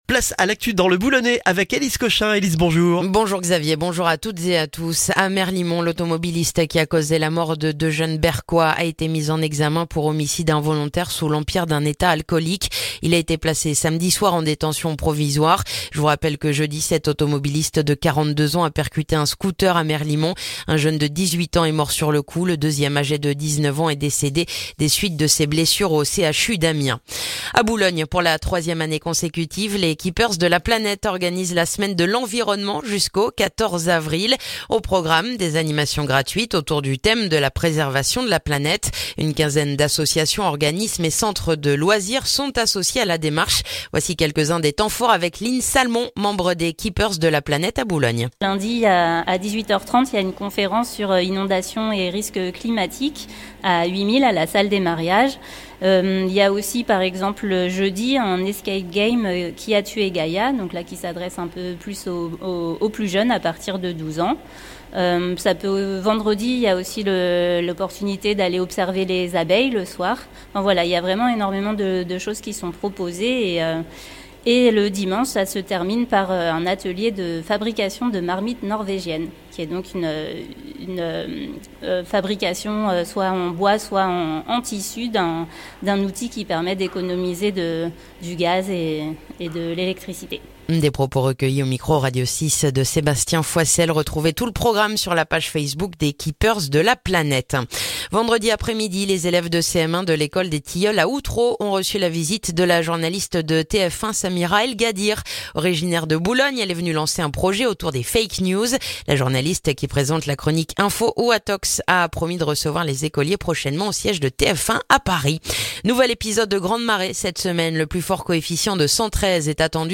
Le journal du lundi 8 avril dans le boulonnais